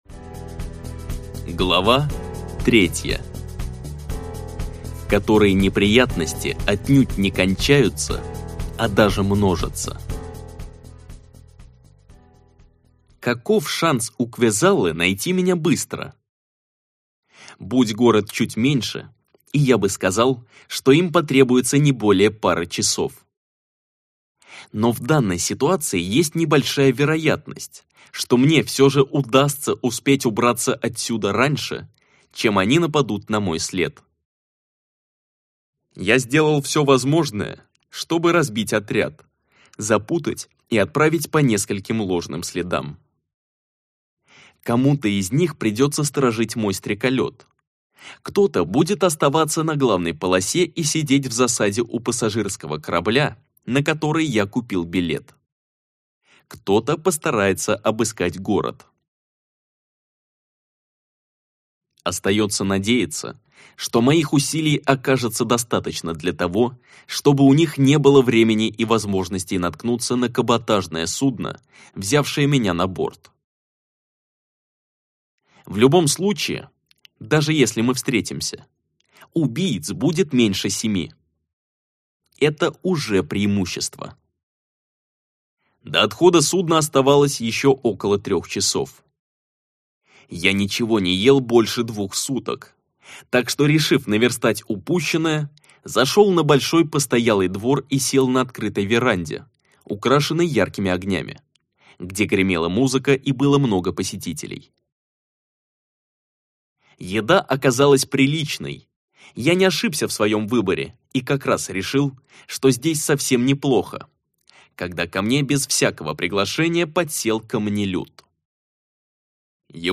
Аудиокнига Ловцы удачи - купить, скачать и слушать онлайн | КнигоПоиск